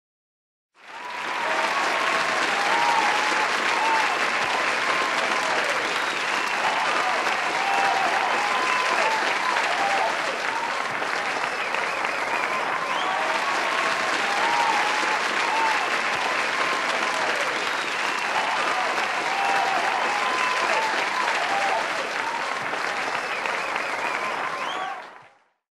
aplaudiendo people Download
APLAUSOS.mp3